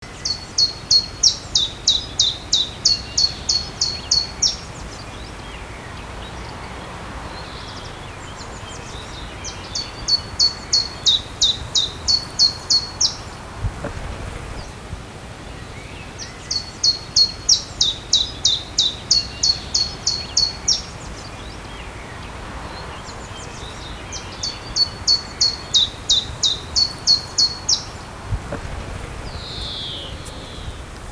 pierwiosnek śpiew